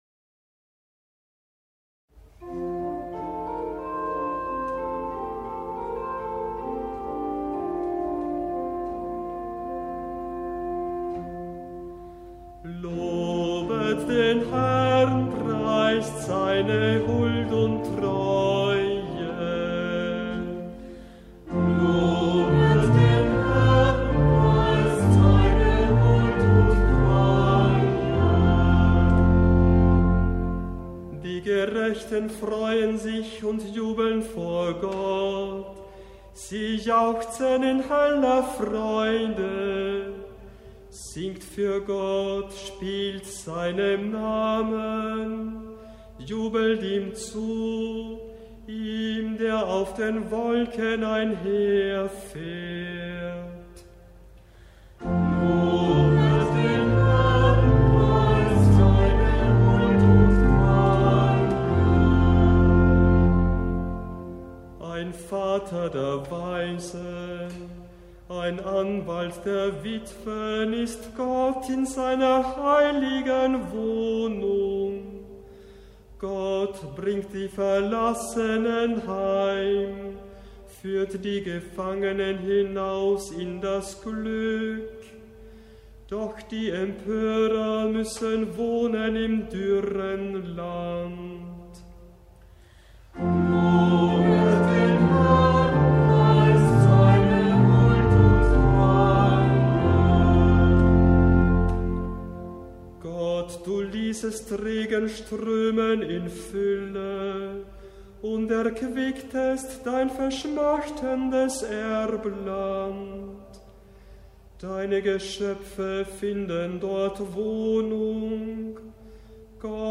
Hörbeispiele aus verchiedenen Kantorenbüchern